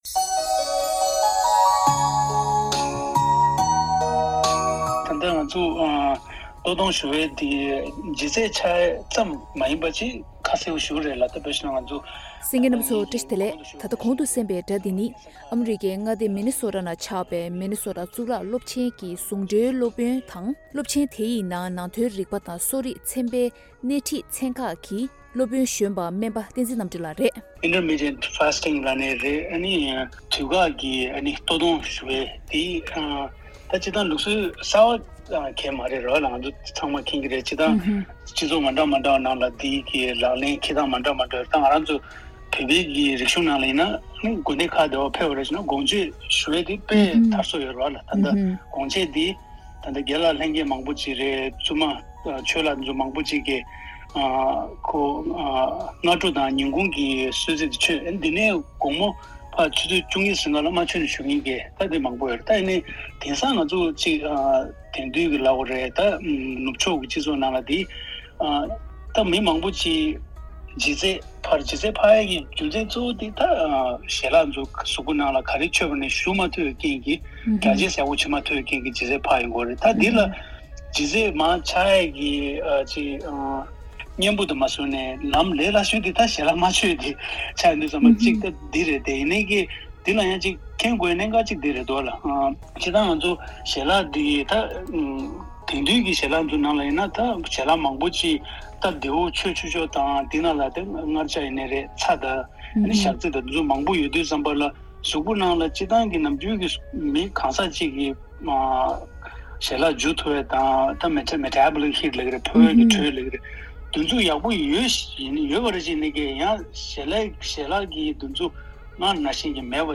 བཀའ་འདྲི་ཞུས་པར་གསན་རོགས༎